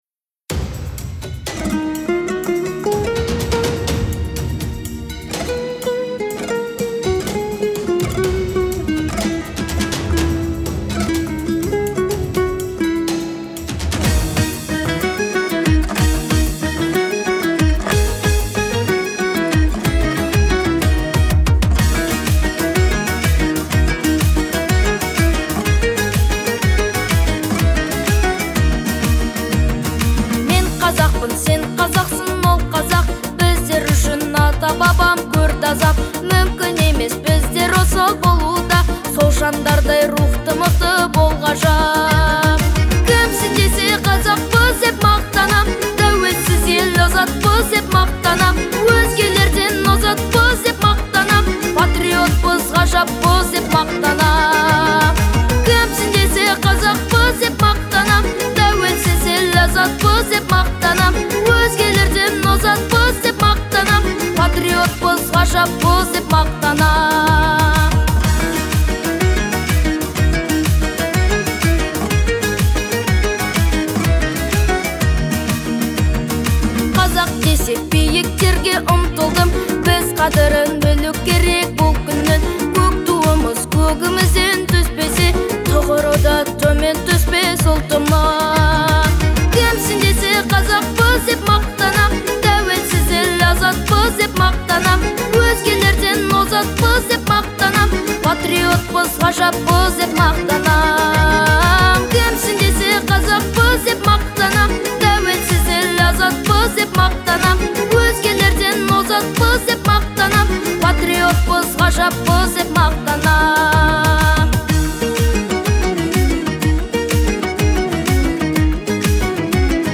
• Жанр: Казахские песни